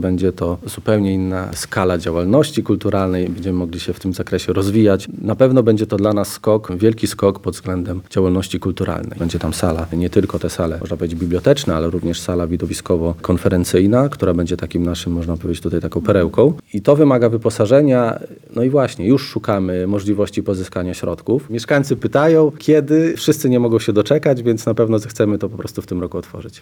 – Budynek będzie przede wszystkim nową siedzibą dla naszej biblioteki publicznej, która dziś bardzo potrzebuje większej przestrzeni – mówi wójt gminy Rudnik Paweł Kucharczyk.